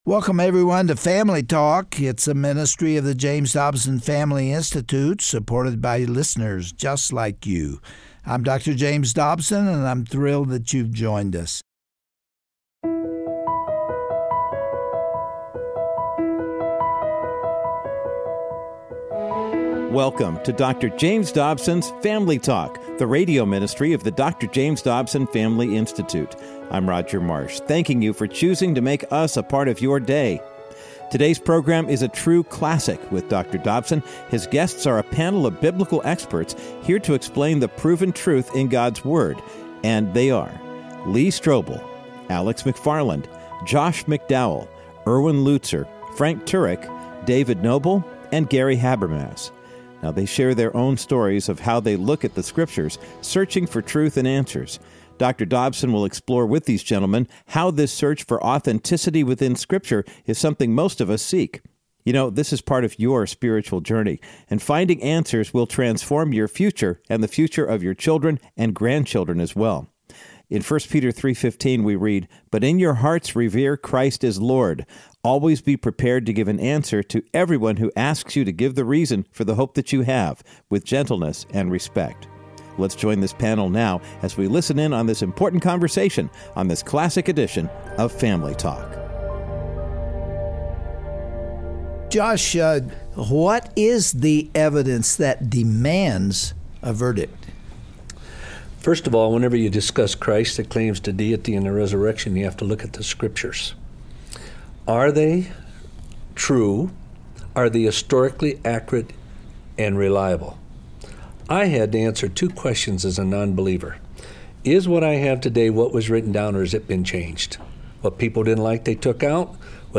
Have you ever had tough questions about Christianity? On today’s classic edition of Family Talk, Dr. James Dobson and several of America’s leading apologists address your concerns.
Host Dr. James Dobson